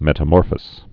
(mĕtə-môrfəs)